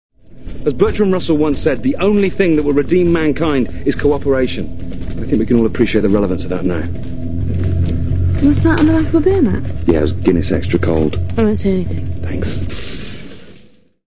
FILM QUOTES